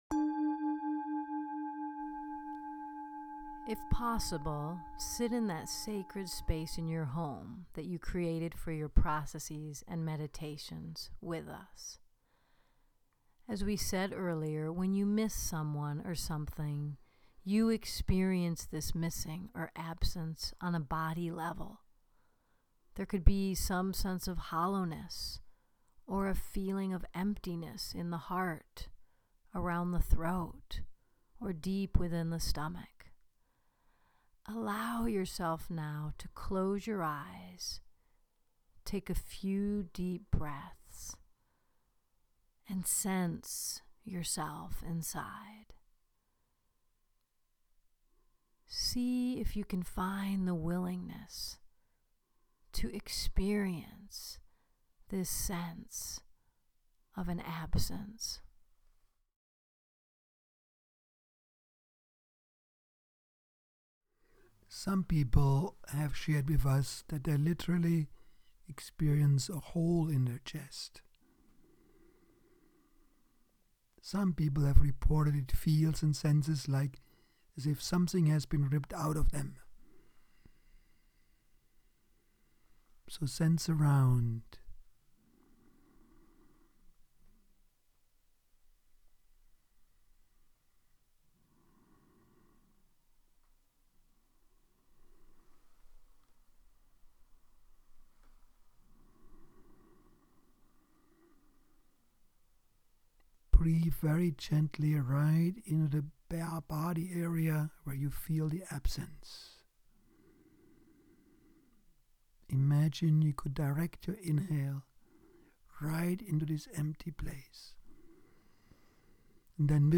Module 4 Track 5 – Guided Meditation: Resting in Absence- Conscious Grieving Process